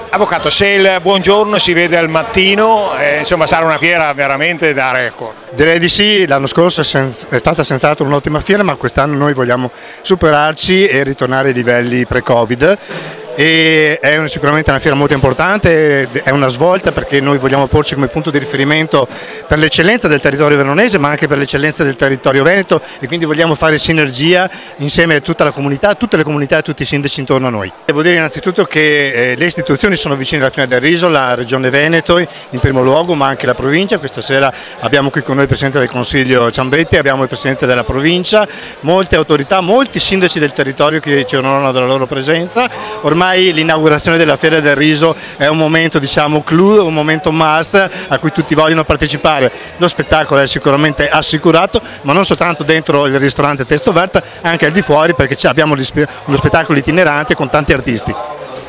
all’evento inaugurale: